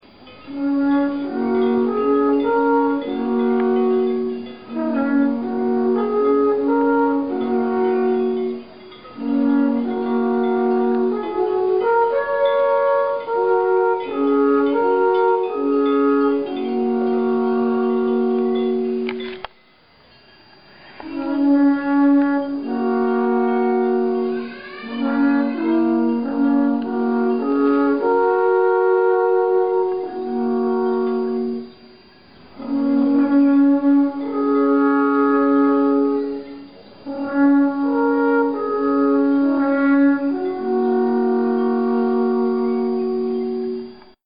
Hearing Switzerland through the windows
Last Sunday, we suddenly heard an odd sound coming through the windows.
Sure enough, there were a couple of men playing alphorns right outside of our window on the courtyard! The alphorn is a traditional Swiss instrument that looks like a very large trumpet, made from wood.
If you listen carefully, you will also hear cowbells in the recording.
Alphorns.mp3